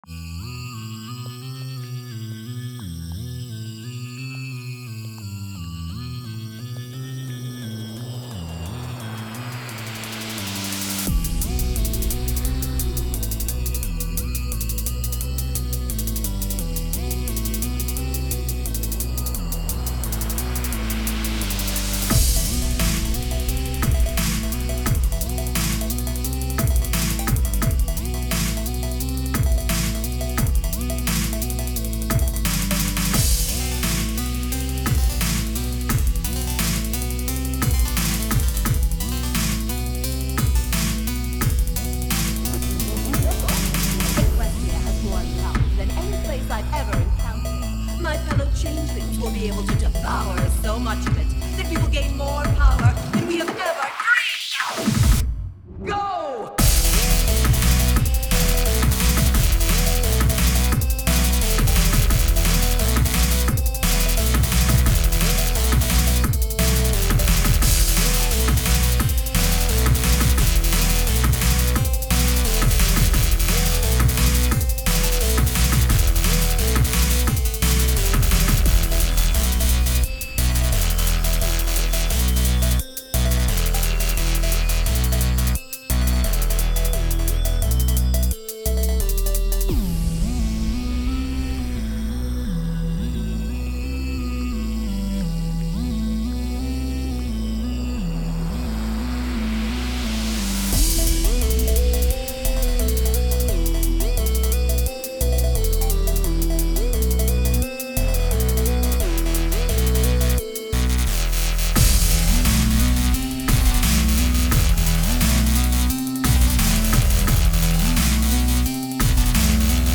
I enjoyed it, sounds like drumstep to me but i may be just wrong ;)